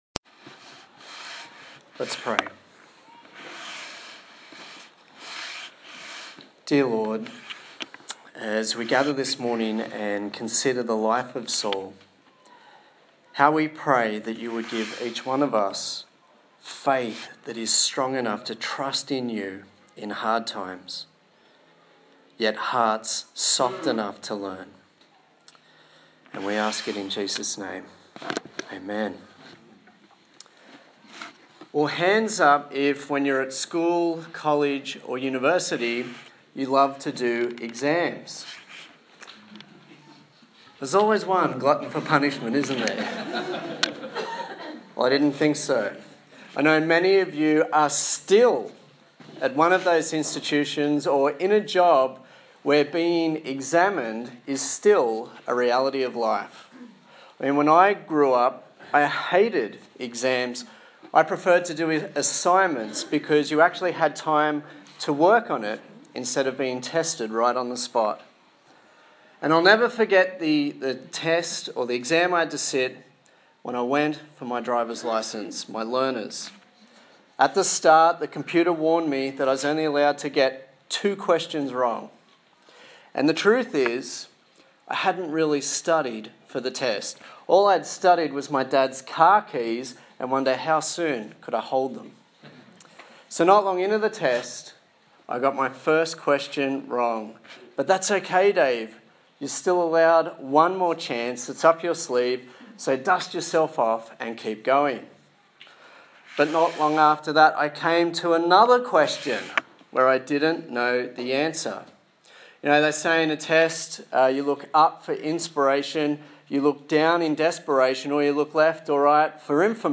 1 Samuel Passage: 1 Samuel 13 Service Type: Sunday Morning